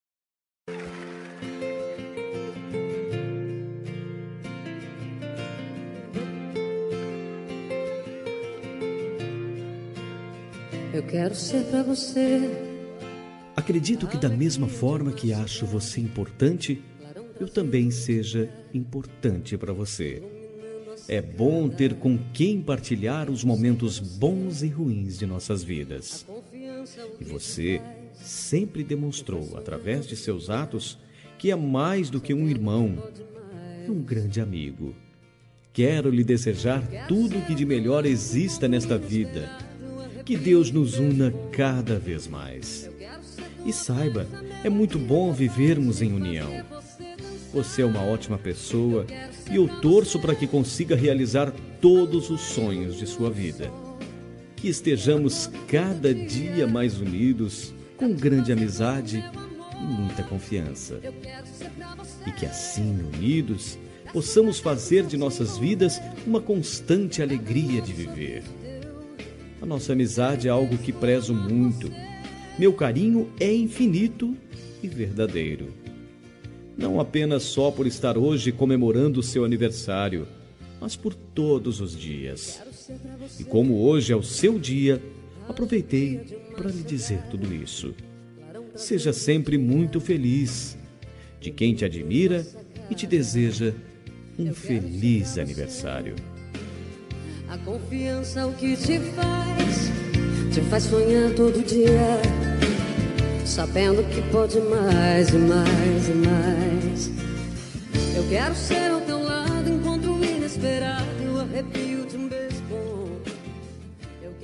Telemensagem de Aniversário de Irmão – Voz Masculina – Cód: 4030